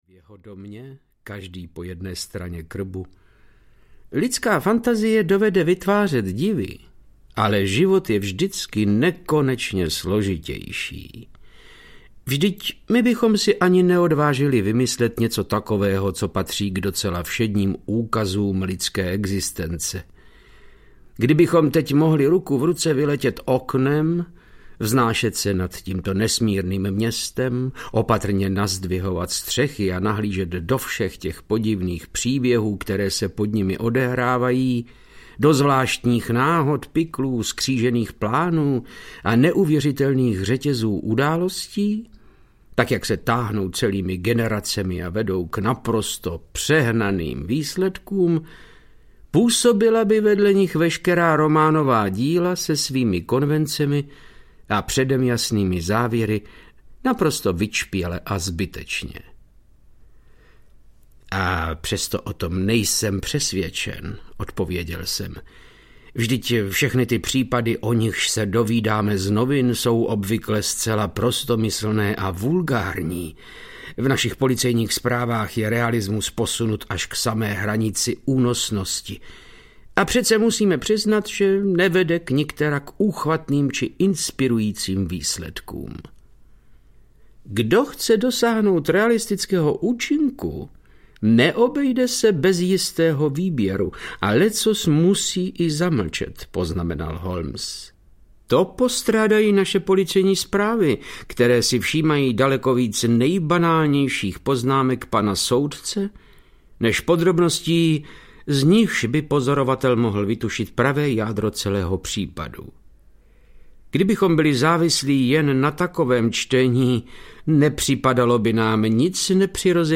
Případ totožnosti audiokniha
Ukázka z knihy
• InterpretVáclav Knop